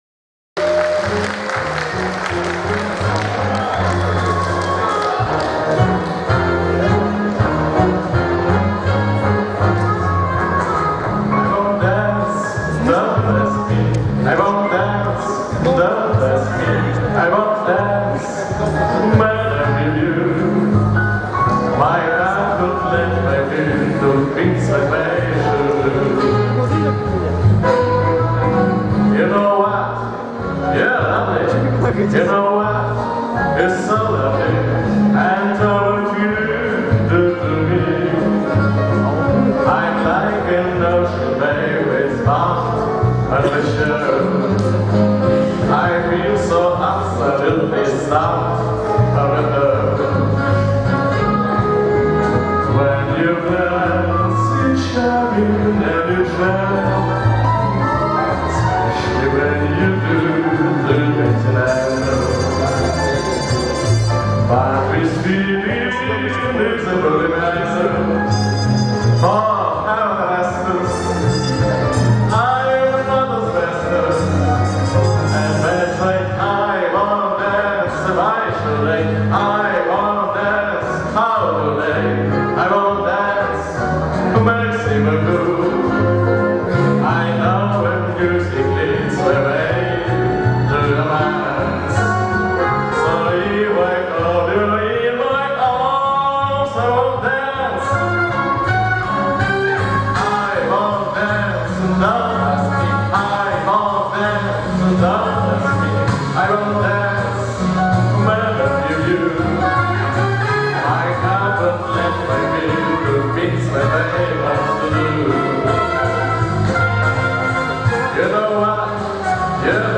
концертная запись